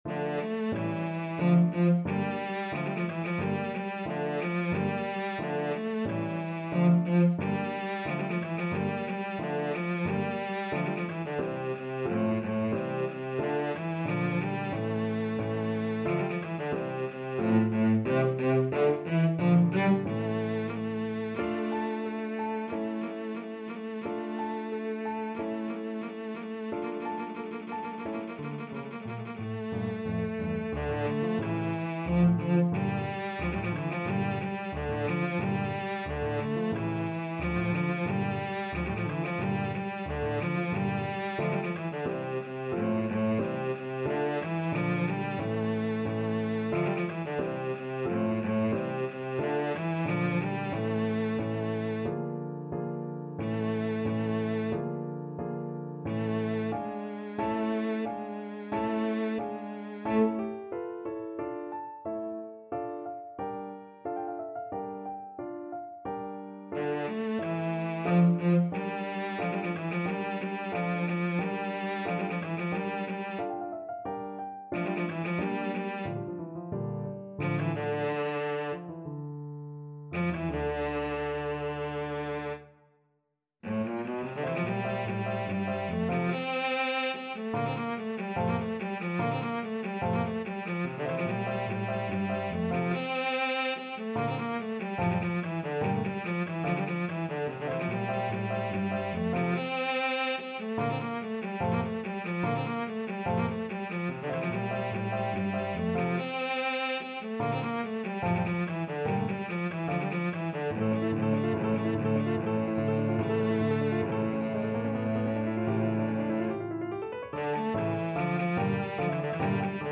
Cello version
CelloPiano
Andantino = 90 (View more music marked Andantino)
2/4 (View more 2/4 Music)
Classical (View more Classical Cello Music)
Russian